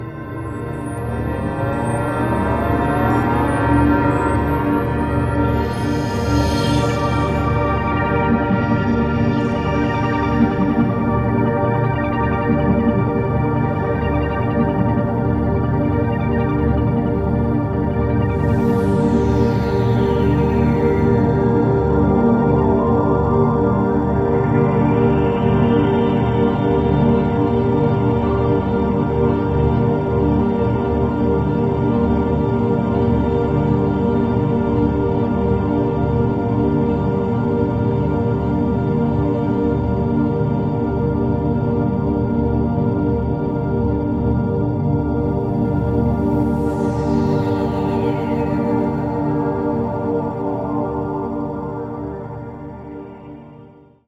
Rebalancing and uplifting.